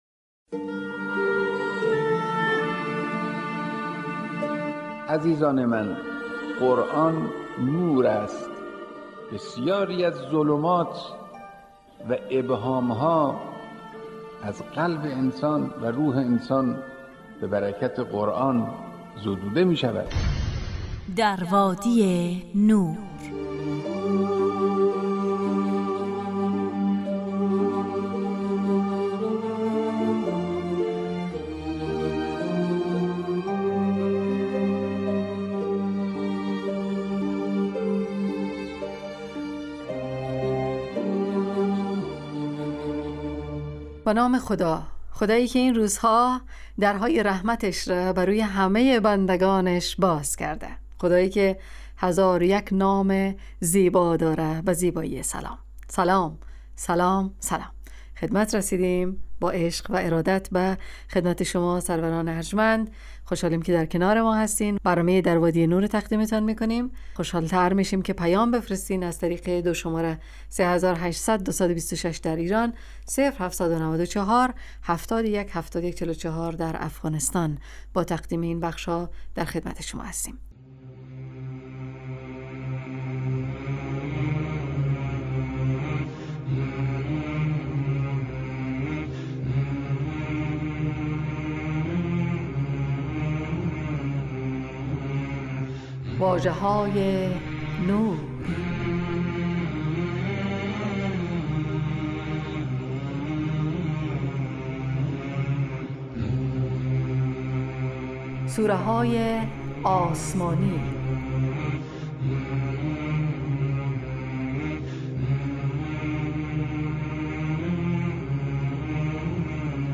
ایستگاه تلاوت
گویندگان: خانمها